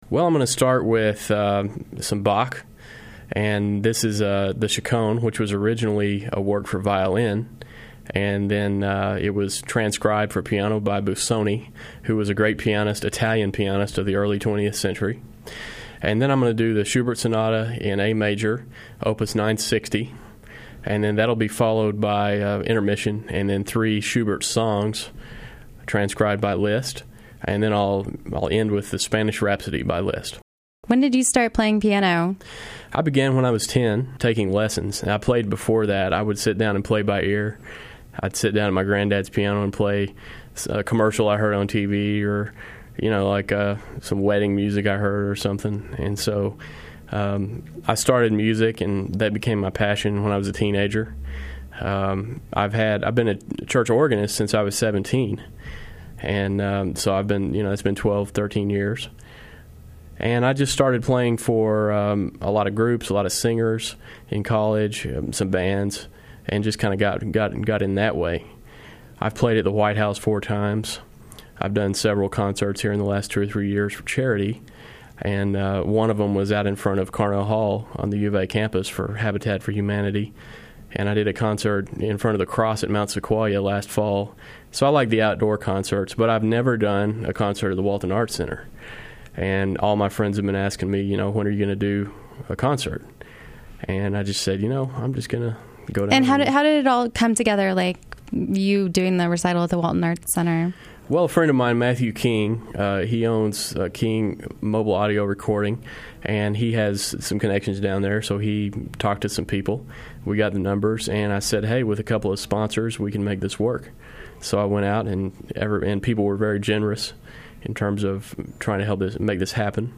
Pianist
the Firmin-Garner Performance studio